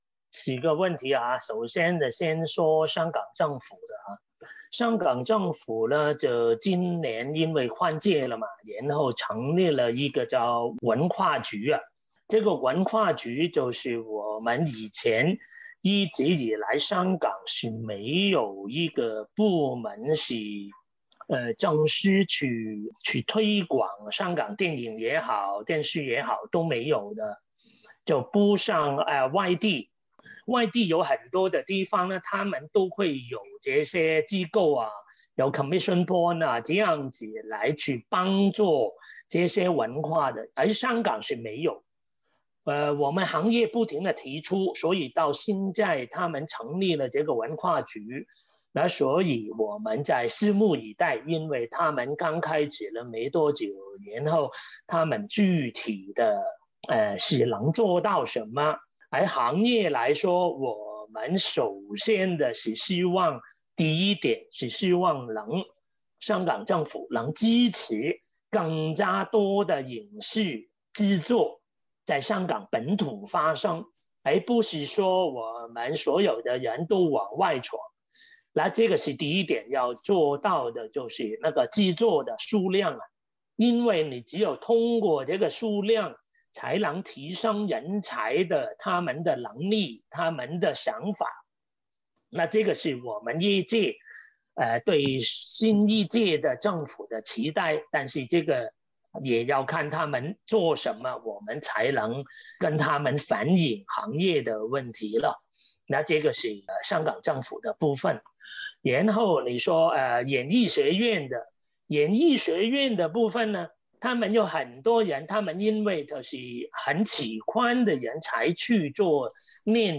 SBS全新谈话类节目《对话后浪》，倾听普通人的烦恼，了解普通人的欢乐，走进普通人的生活。
本期话题：被港产片神助攻的爱情。点击收听风趣对话。